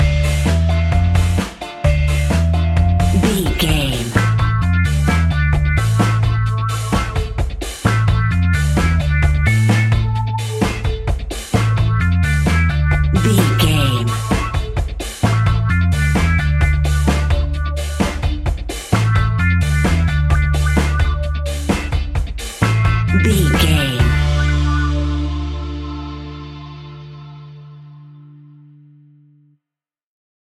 Classic reggae music with that skank bounce reggae feeling.
Aeolian/Minor
D
dub
laid back
chilled
off beat
drums
skank guitar
hammond organ
transistor guitar
percussion
horns